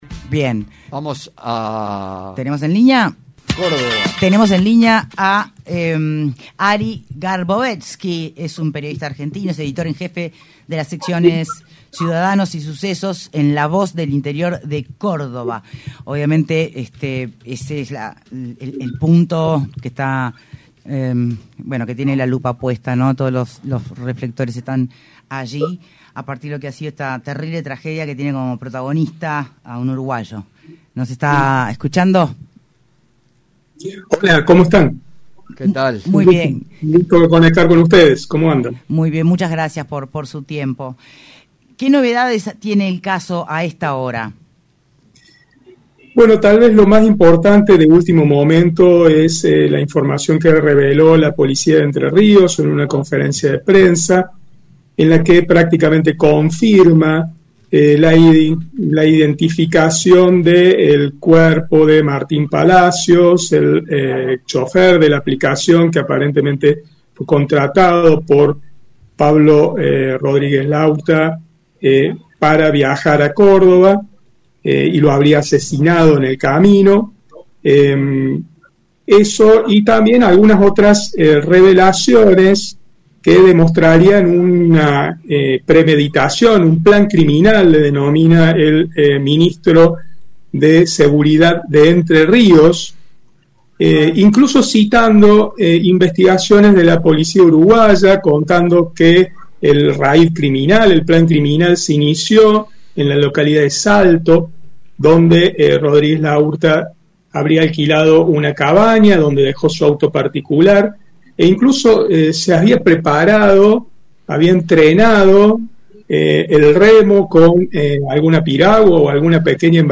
En diálogo con Punto de Encuentro